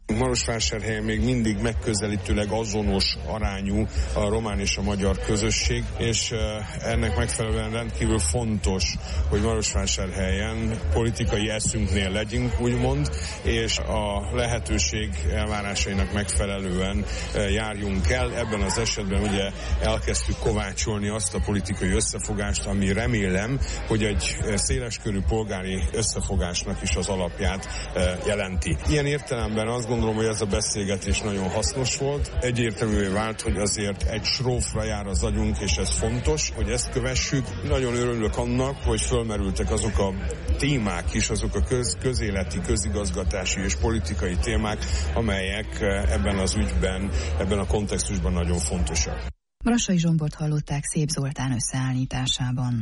Brassai Zsombor Maros megyei RMDSZ-elnök így nyilatkozott rádiónknak a beszélgetésről.